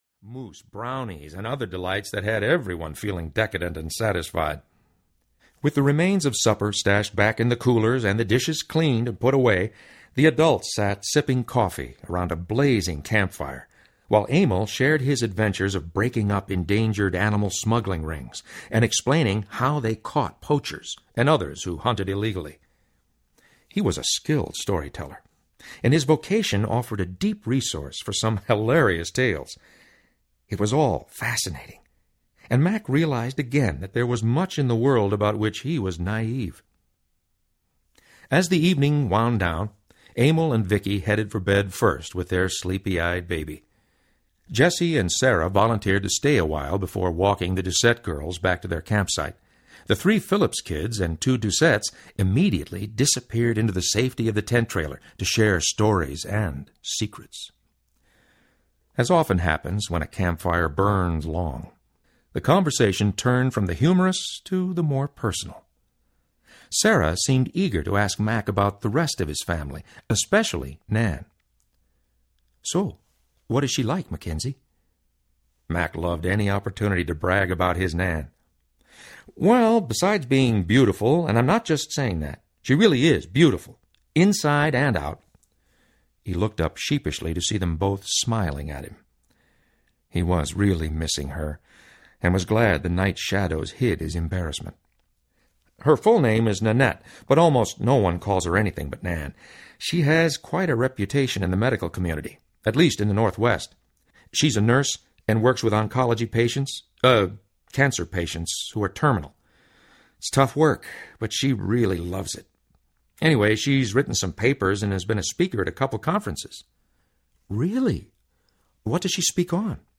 The Shack Audiobook
9.25 Hrs. – Unabridged